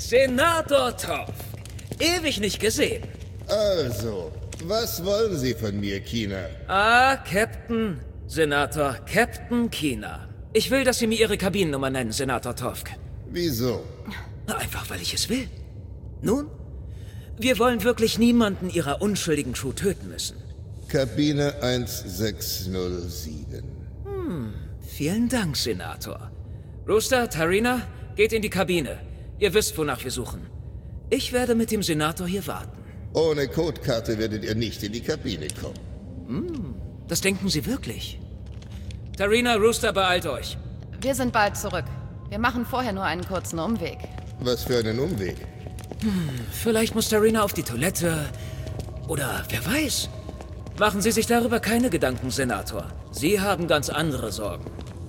Klar, markant, frisch, wandelbar, witzig, sinnlich.
Hörspiel/Hörbuch